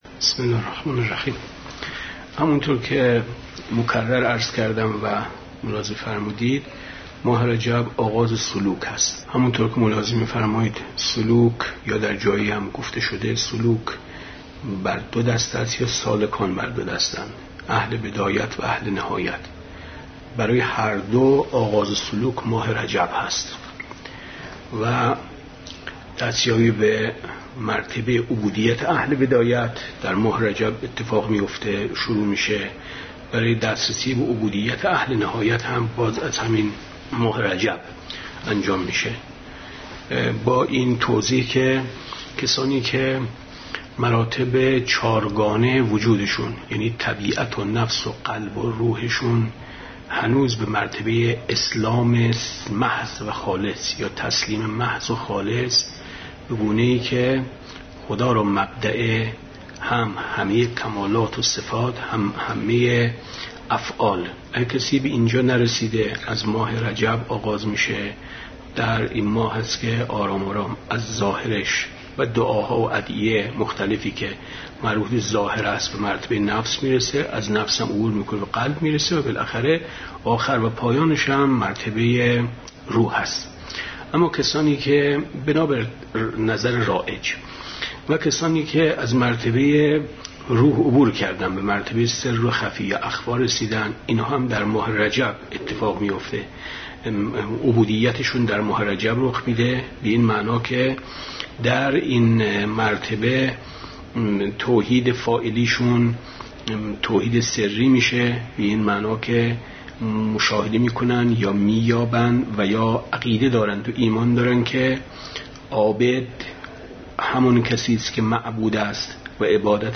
گفتاری
گزیده درس 69 شرح خطبه پارسایان